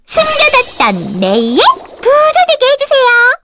May-Vox_Skill4_kr.wav